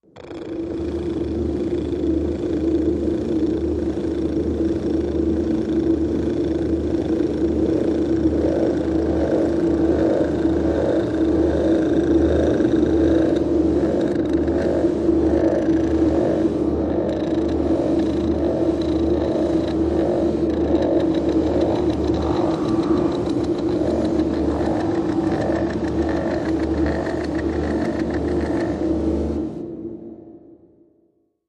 Foundry Cat, Machine, Purr, Ambient Drone, Factory Reverb, Atmosphere